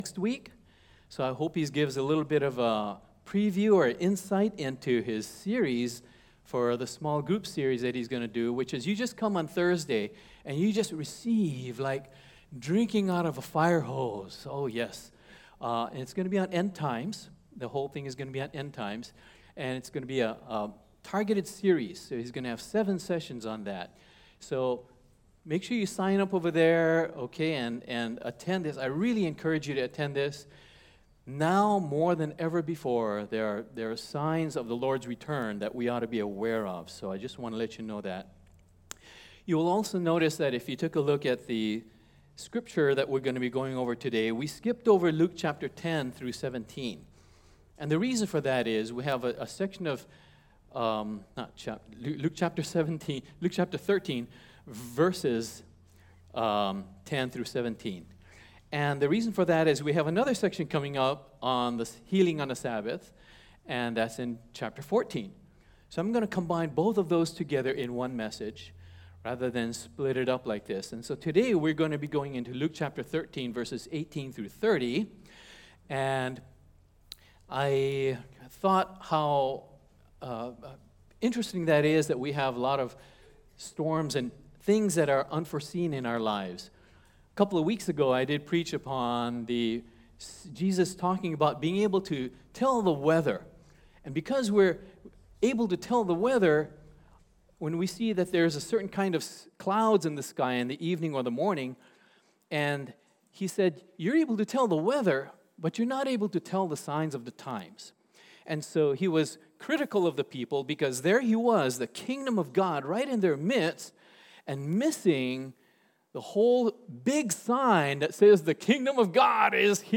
Sermons 2018-09-23 What did Jesus say to do, to all believers? Sermon